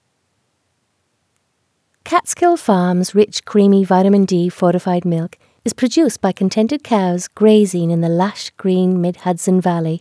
Final processing reduces peak to -4.5 dB, is this acceptable "quality"?
I have simply doubled the audio track, so it renders in the video twice over. The result is much “louder”: